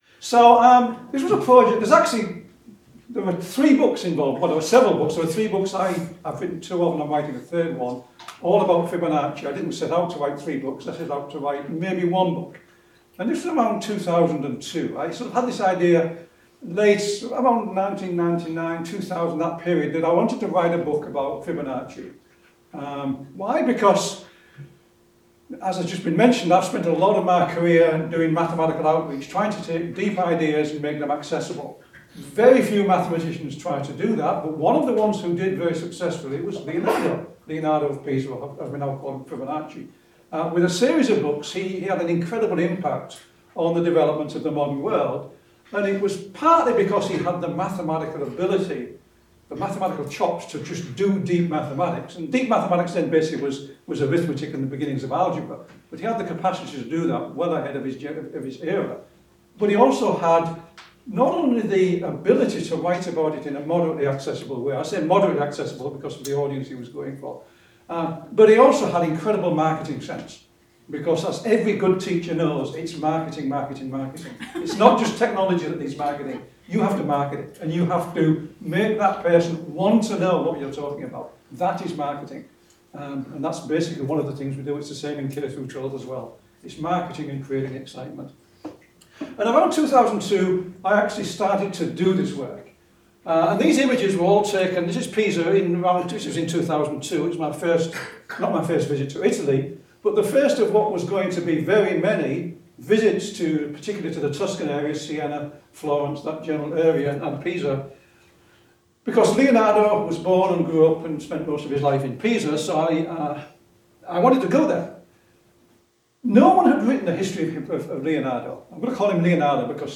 Devlin - Kitao Lecture
Keith Devlin presents this year's Kaori Kitao Lecture.